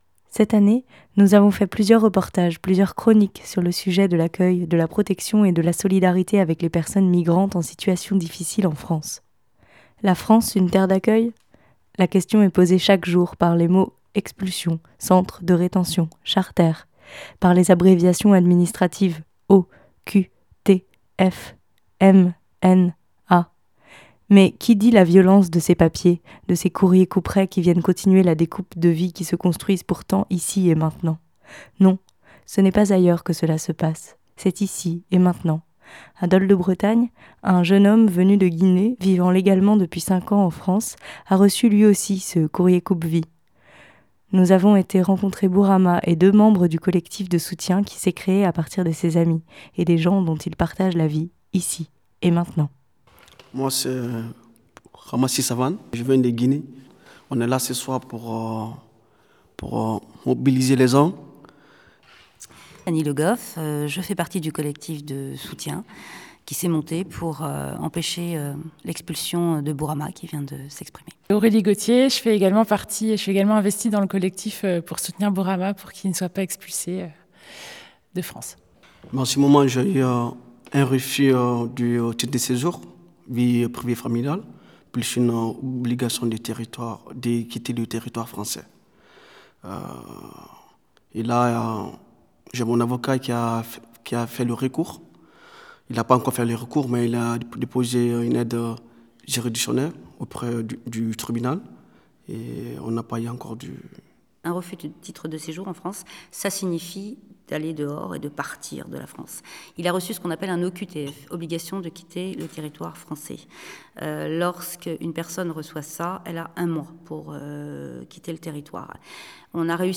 Entretien. http